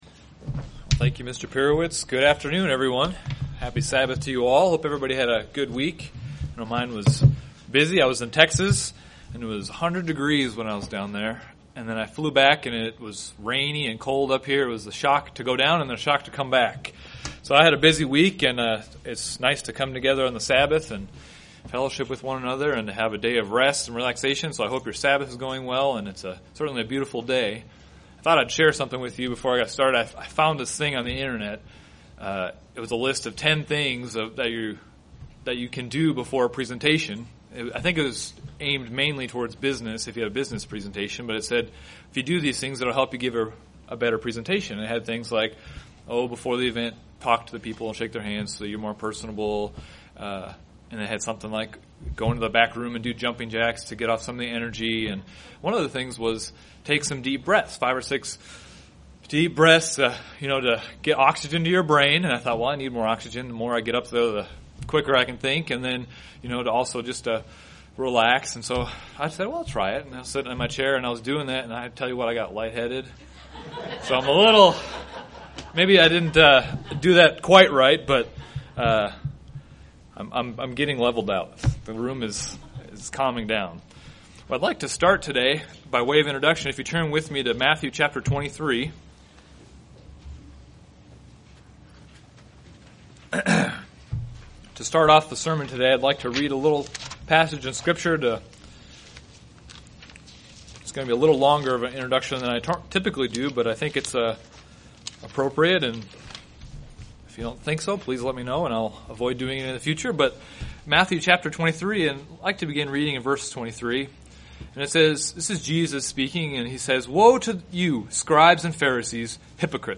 Should a Christian judge? This sermon looks into the topic of Judgment, one the weighter matters of the law.
UCG Sermon Studying the bible?